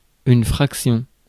Ääntäminen
Synonyymit pouième Ääntäminen France: IPA: [fʁak.sjɔ̃] Haettu sana löytyi näillä lähdekielillä: ranska Käännös Substantiivit 1. фракция {f} 2. дроб {f} (drob) Muut/tuntemattomat 3. част {f} (čast) 4. частица {f} Suku: f .